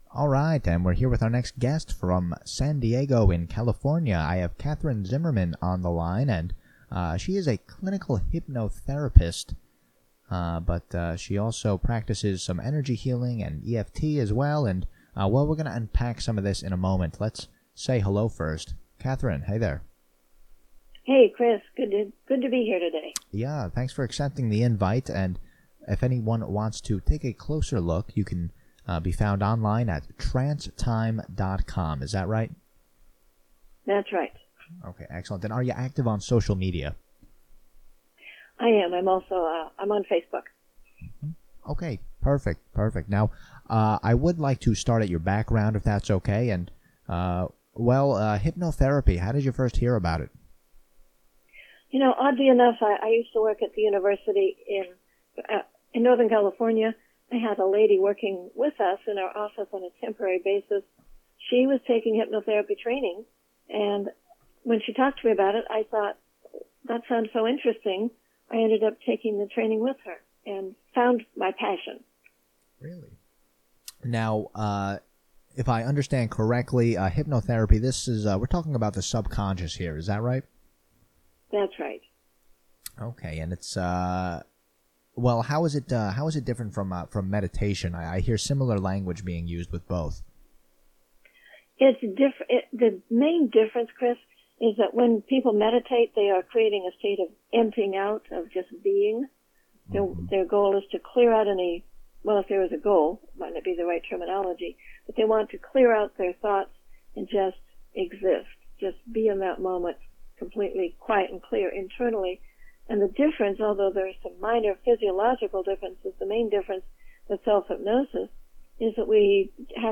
Mindbodyradio Interview